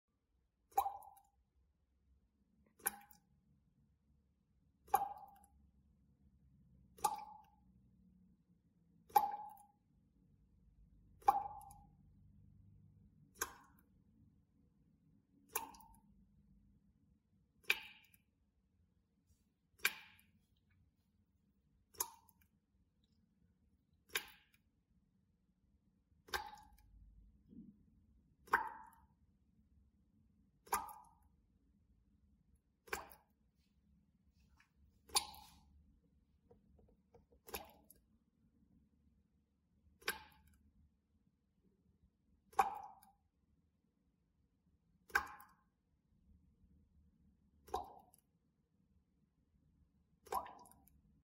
Звуки водопровода
На этой странице собраны разнообразные звуки водопровода: от тихого журчания до резких стуков в трубах.